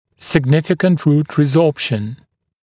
[sɪg’nɪfɪkənt ruːt rɪˈzɔːpʃn] [-ˈsɔːp-][сиг’нификэнт ру:т риˈзо:пшн] [-ˈсо:п-]существенная резорбция корня